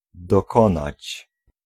Ääntäminen
Synonyymit uitmaken klaarkomen met afschaffen stoppen afwerken eindigen opzeggen afsluiten besluiten afmaken afronden staken volbrengen voleindigen afschrijven Ääntäminen Tuntematon aksentti: IPA: /bəˈɛi̯ndəɣə(n)/